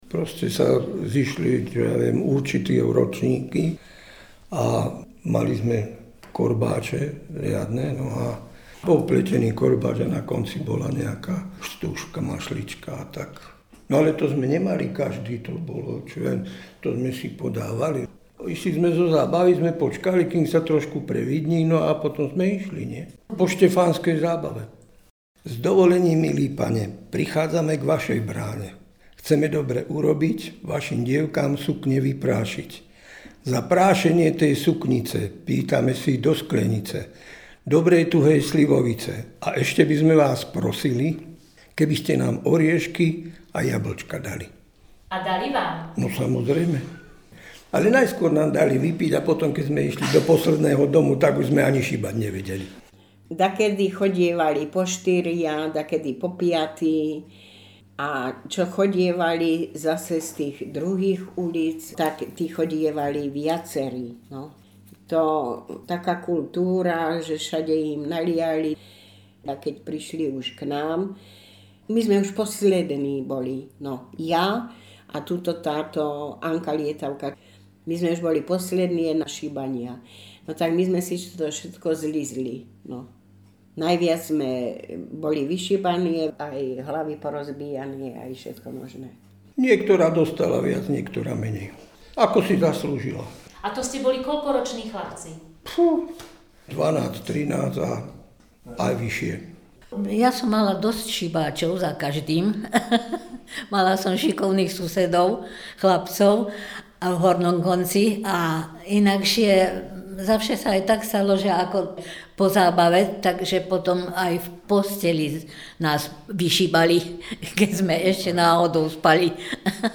1. Popis spomienkové rozprávanie obyvateľov obce Lieskovec o šibaní dievok na deň Štefana
Miesto záznamu Lieskovec